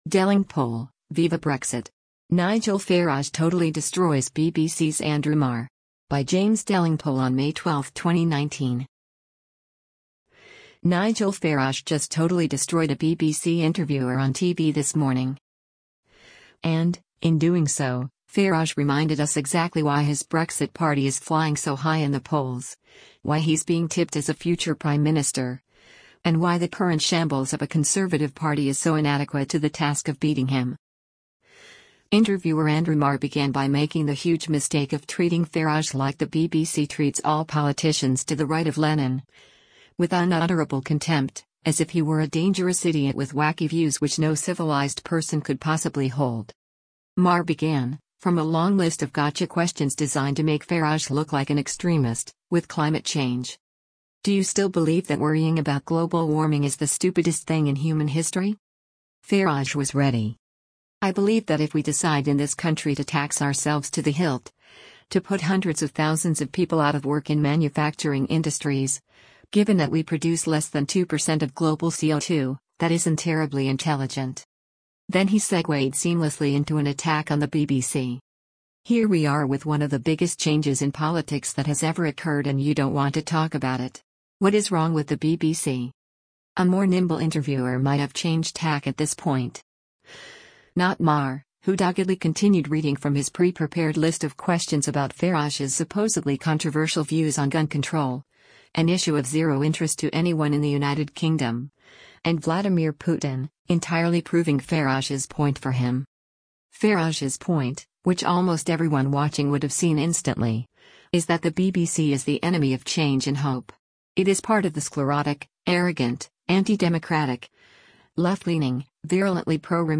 Nigel Farage just totally destroyed a BBC interviewer on TV this morning.
“Do you still feel that people with HIV shouldn’t be allowed into the United Kingdom?” Marr continued, still reading from his notes, his voice a bit tremulous at this point.
Marr was starting to sound pathetic now.